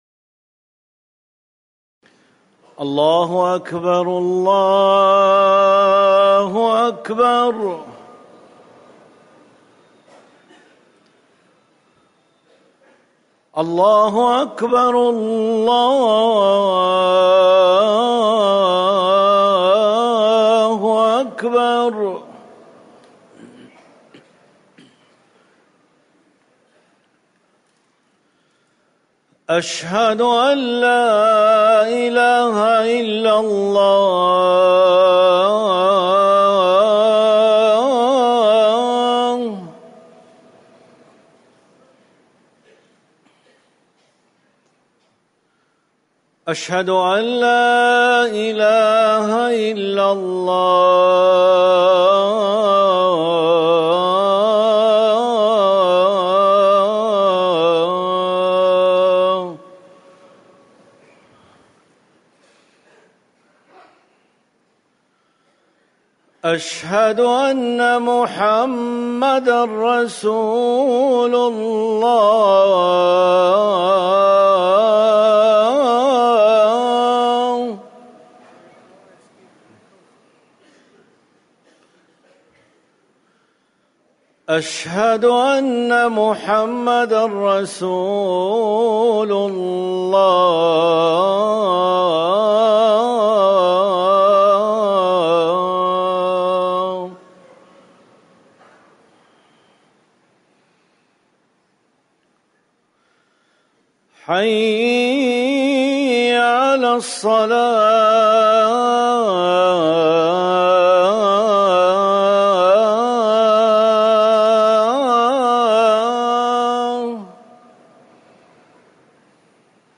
أذان الفجر الثاني - الموقع الرسمي لرئاسة الشؤون الدينية بالمسجد النبوي والمسجد الحرام
تاريخ النشر ١٥ محرم ١٤٤١ هـ المكان: المسجد النبوي الشيخ